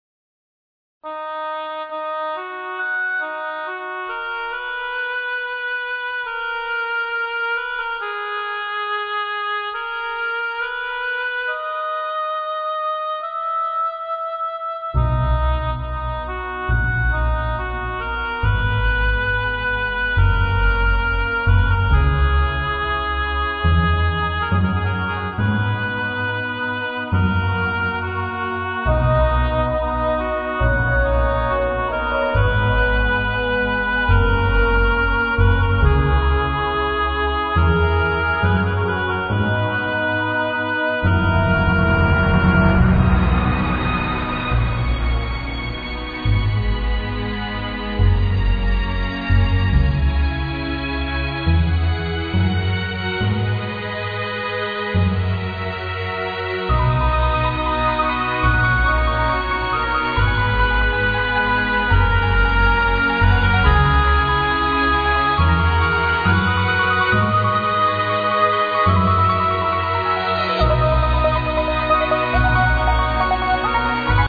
amazing trance tune
p.s its not clasical music.